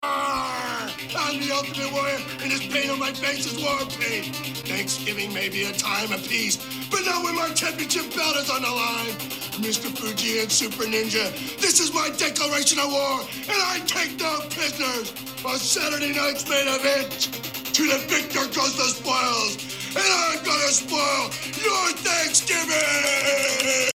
At the show’s opening, Warrior cut the most Warrior promo ever. We get grunting, growling, and chest beating.